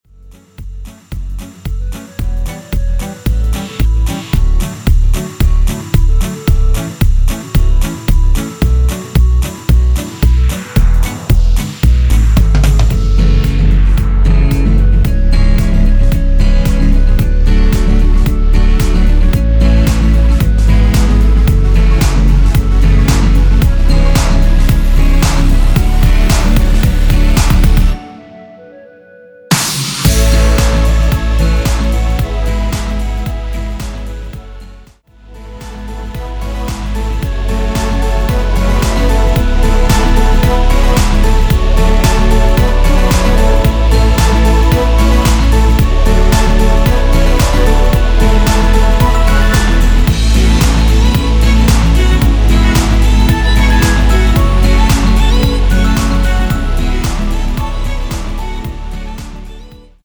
원키에서(-3)내린 멜로디 포함된 MR입니다.
멜로디 MR이라고 합니다.
앞부분30초, 뒷부분30초씩 편집해서 올려 드리고 있습니다.